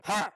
cultistattack.mp3